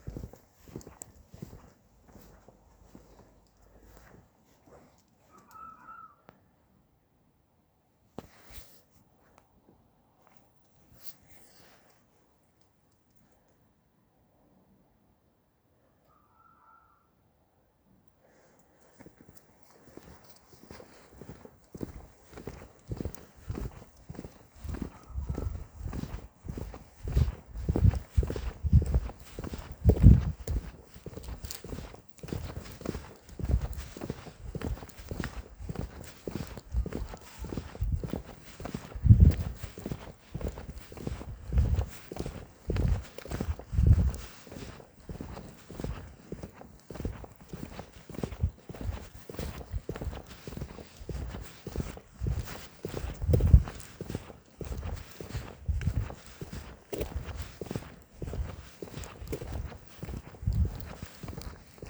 Late night bird call
The area is wooded surrounding untouched meadows in Cornwall. Only managed to catch it in the first 10seconds of the recording so no need to listen through.
However I'm convinced the sound came from up in the trees with no higher ground above me to suggest otherwise.